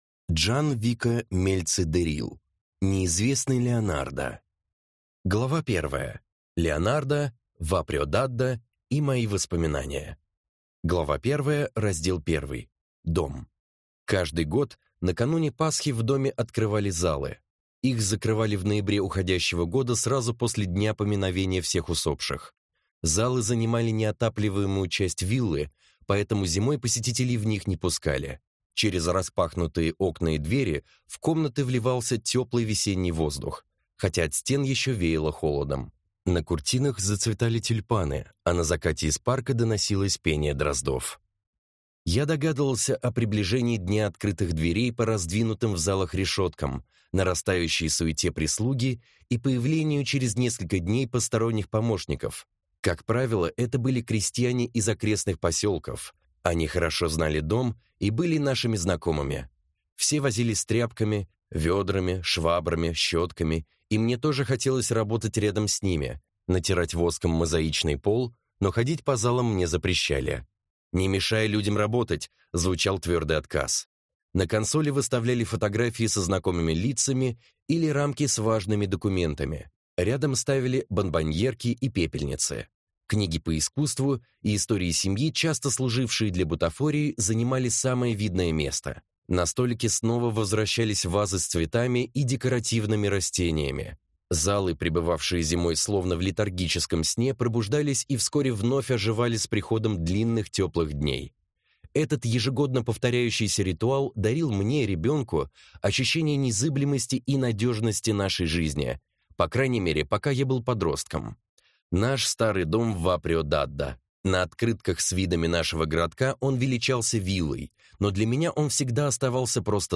Аудиокнига Неизвестный Леонардо | Библиотека аудиокниг